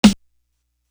Latin Thug Snare.wav